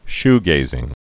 (shgāzĭng) or shoe·gaze (-gāz)